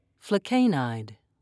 (fle-kay'nide)